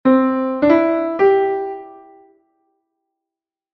unanota.mp3